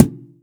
Kicks
KICK_OF_.WAV